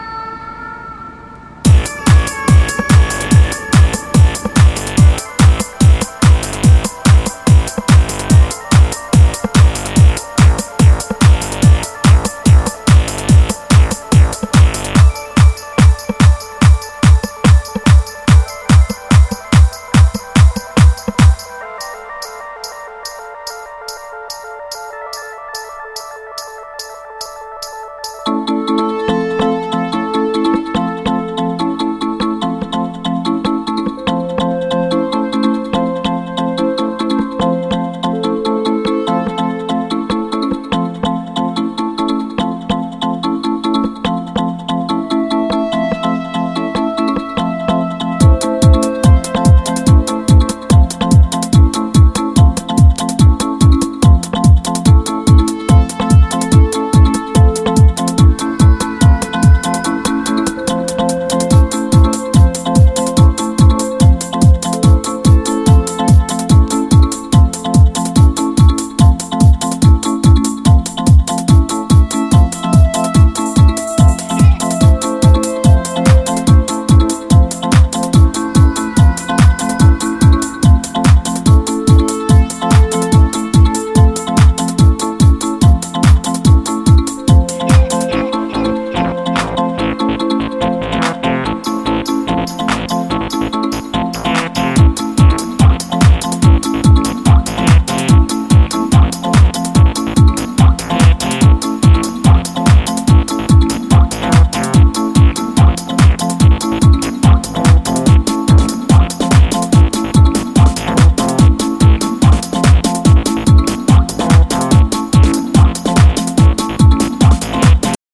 Techno , Trance